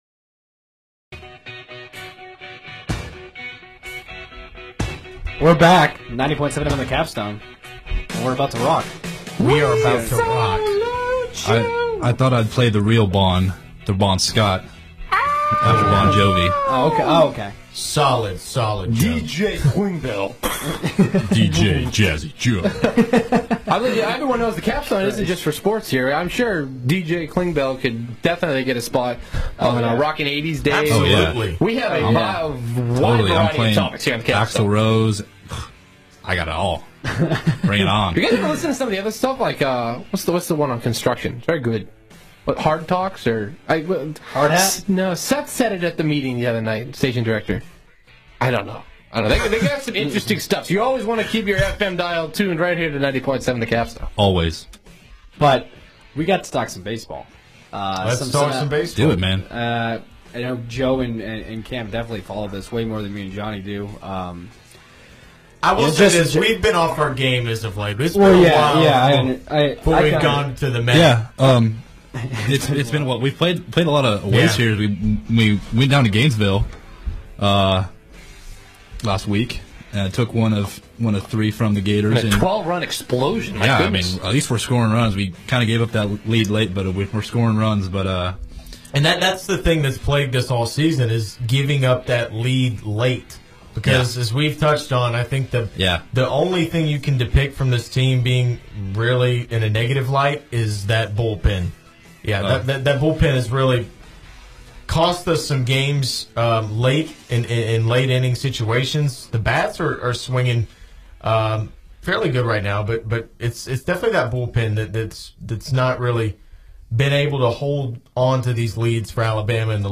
WVUA-FM's and Tuscaloosa's longest running sports talk show "The Student Section"